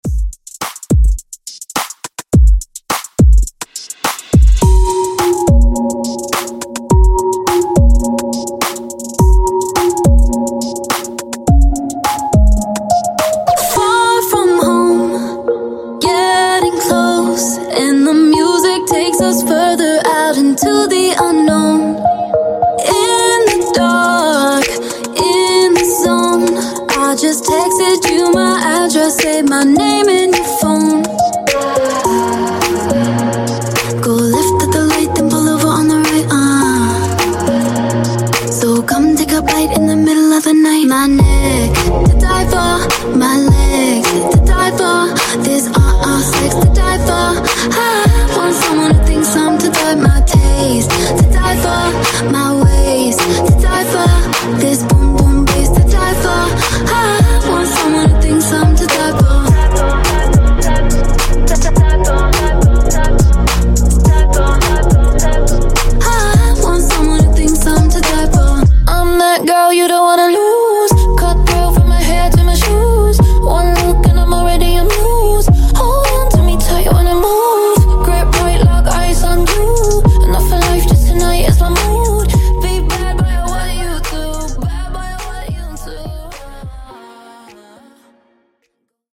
Genre: GERMAN MUSIC
Dirty BPM: 146 Time